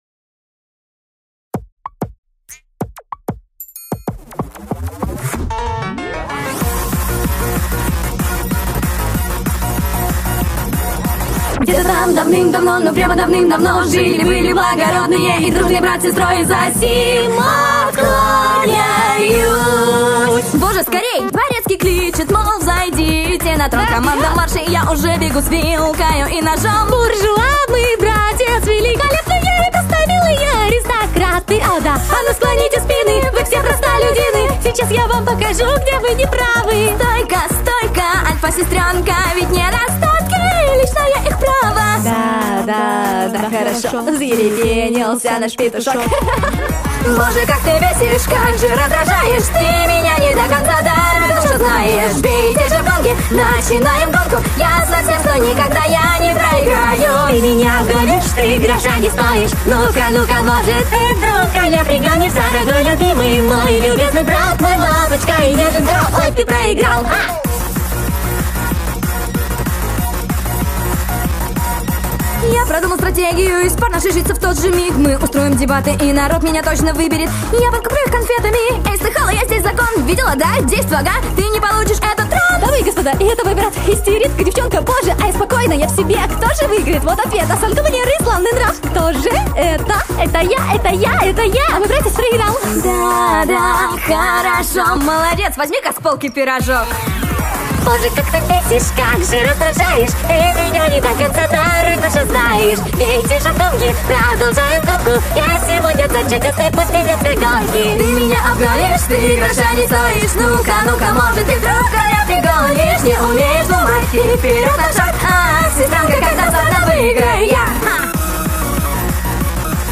Панк